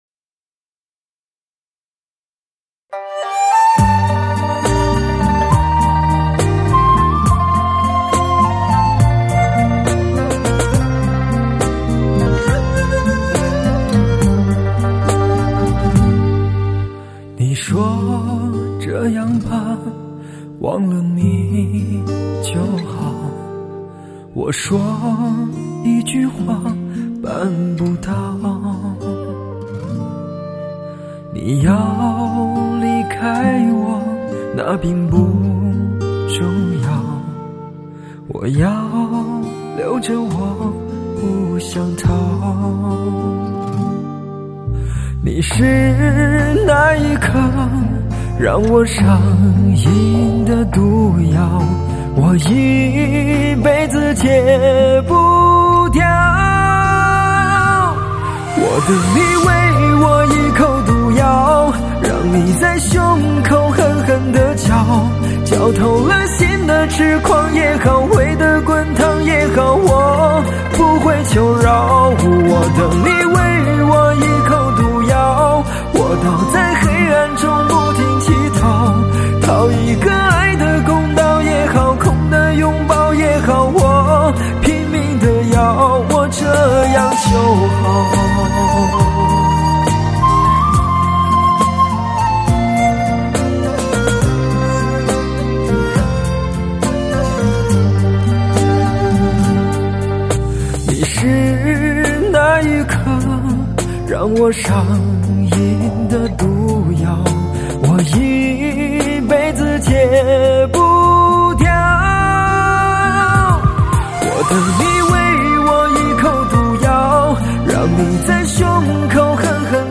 唱片类型：汽车音乐
辑音效极致HIFI男声的汽车音响示范大碟。
极富视听效果的发烧靓声，德国版HD高密度24bit数码录音，顶级发烧器材专业监听精品CD，现今高端压